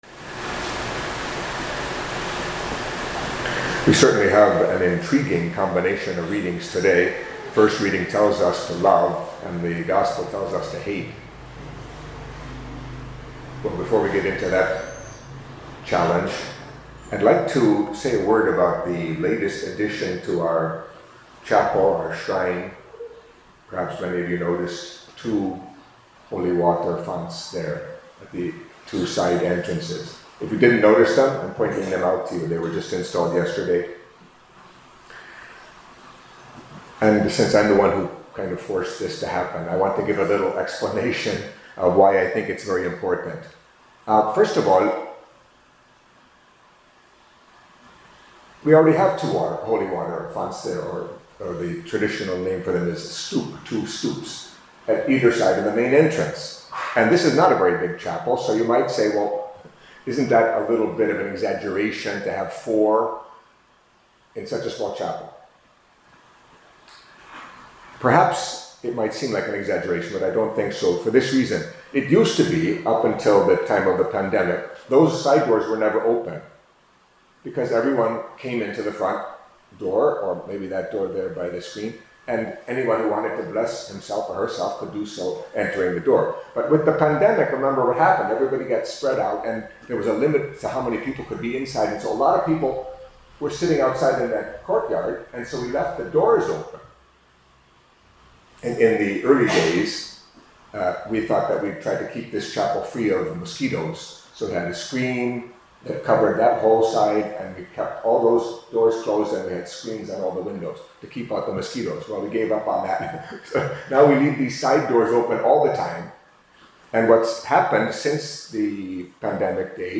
Catholic Mass homily for Wednesday of the Thirty-First Week in Ordinary Time